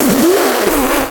Effetti sonori associati
[sputa i liquidi nell'aria]
Taunt_demo_nuke_7_spit.wav